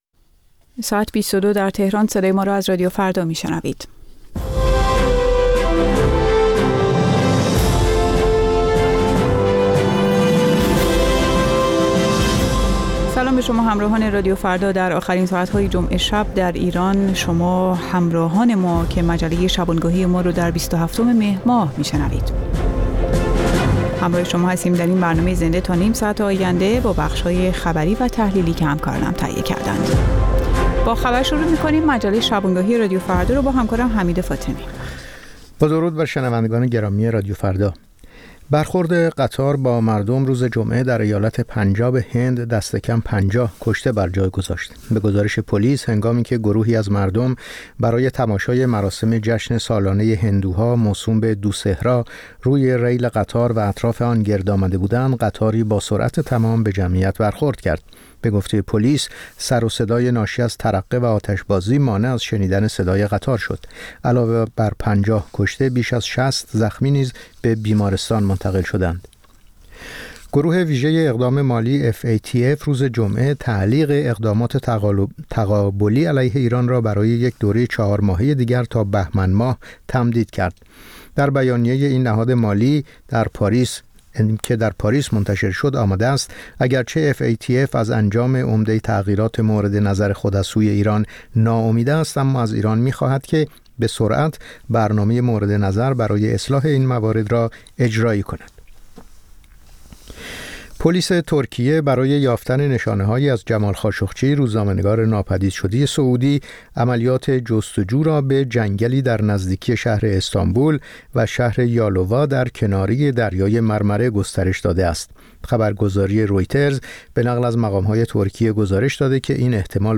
نیم ساعت با تازه‌ترین خبرها، گزارش‌های دست اول در باره آخرین تحولات جهان و ایران از گزارشگران رادیو فردا در چهارگوشه جهان، گفت‌وگوهای اختصاصی با چهره‌های خبرساز و کارشناسان و مطالب شنیدنی از دنیای سیاست، اقتصاد، فرهنگ، دانش و ورزش.